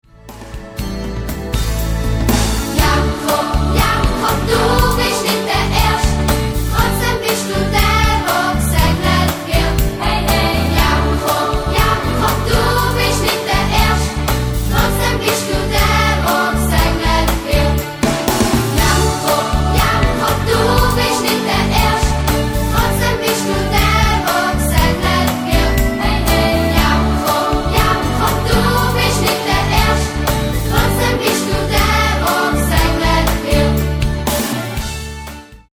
Ruhige Balladen und fetzige Popsongs wechseln sich ab.
Musical-CD mit Download-Code